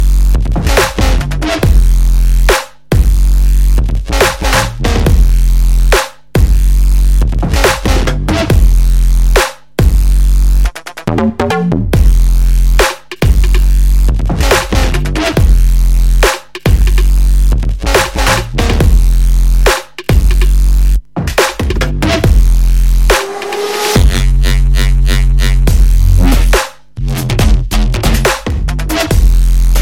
TOP >Vinyl >Grime/Dub-Step/HipHop/Juke